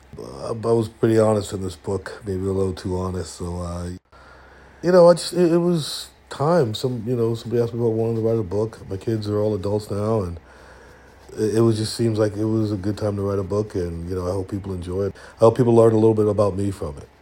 Darius Rucker talks about releasing his memoir.